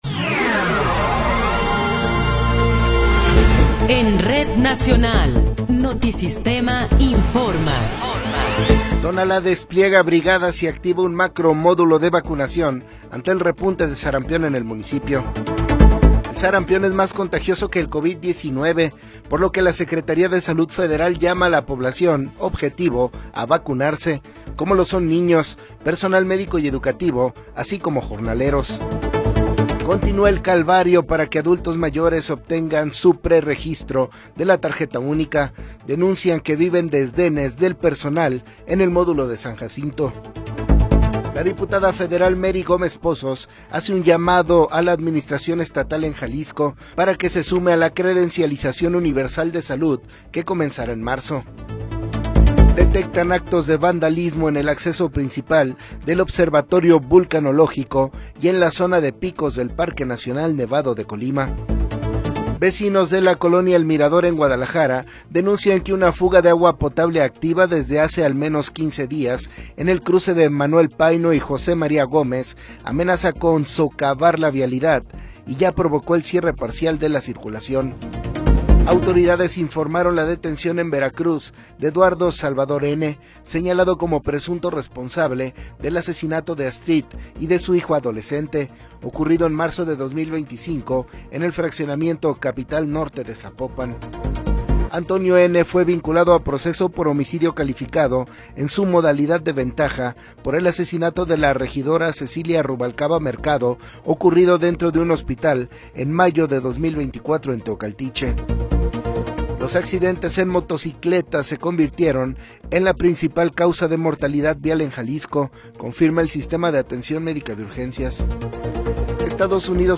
Noticiero 21 hrs. – 21 de Enero de 2026
Resumen informativo Notisistema, la mejor y más completa información cada hora en la hora.